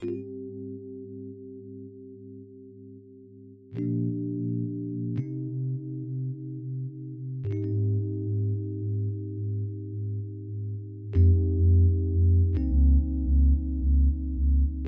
01 rhodes B1.wav